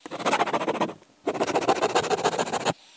Pen Scribble Loop